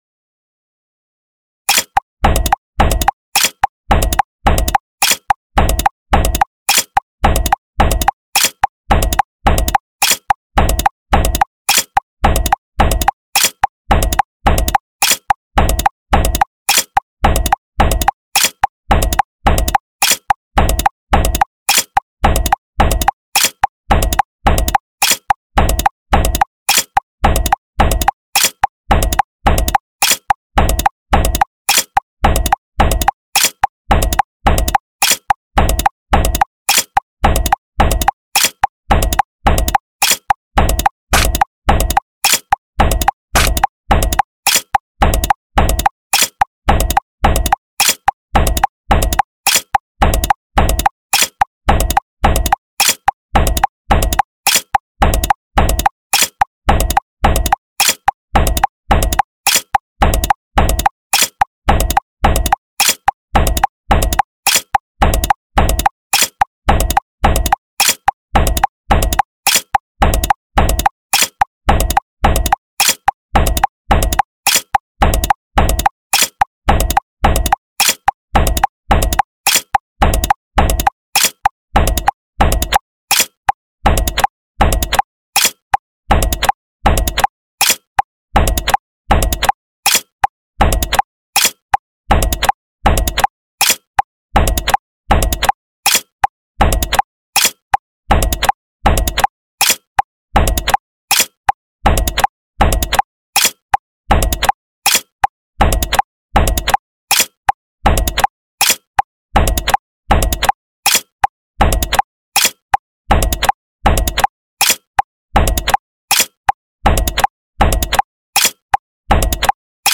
Only Click Sound
EverythingFallsAway_only_Click.mp3